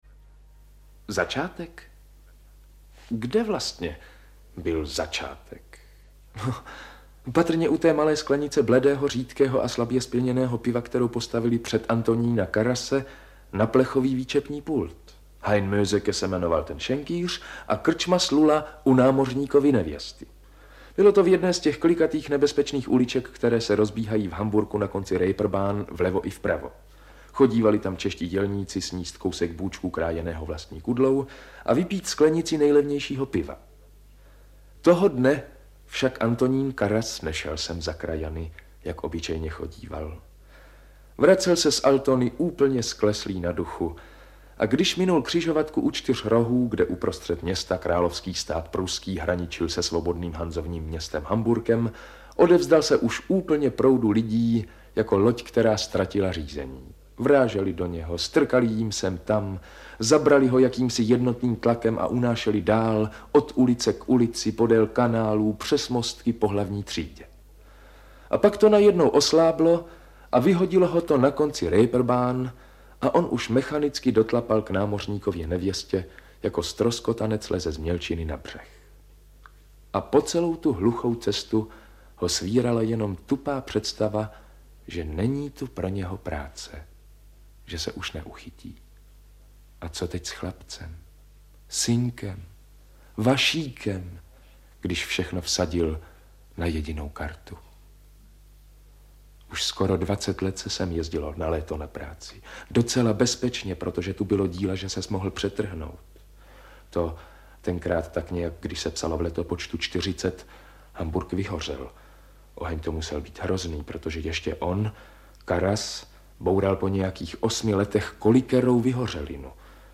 Klasická kniha české literatury je poutavým a procítěným vyprávěním o čtyřech generacích slavné rodiny cirkusových umělců. Tentokrát jako mluvené slovo - Cirkus Humberto čtený Rudolfem Pellarem.
AudioKniha ke stažení, 12 x mp3, délka 5 hod. 41 min., velikost 624,0 MB, česky